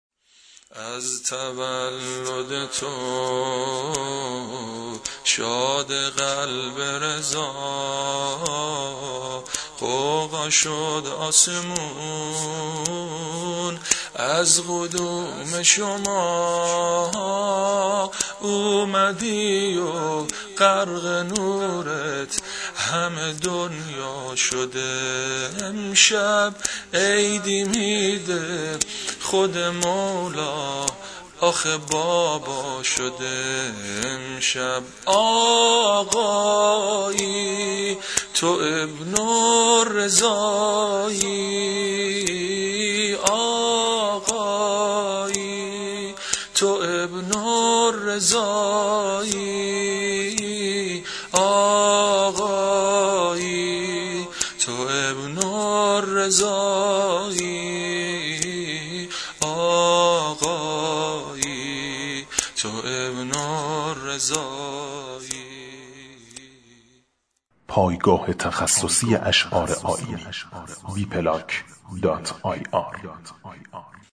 محمد الجواد ولادت سرود